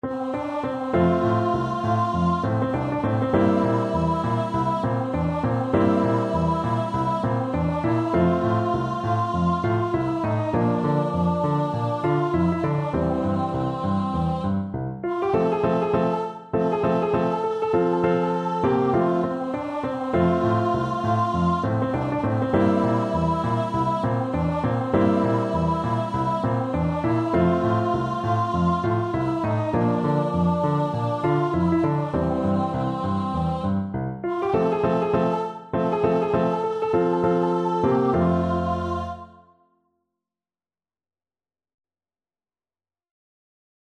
Childrens Childrens Voice Sheet Music Down By The Bay
Voice
F major (Sounding Pitch) (View more F major Music for Voice )
Quick two in a bar = c.100
down_by_the_bay_VOICE.mp3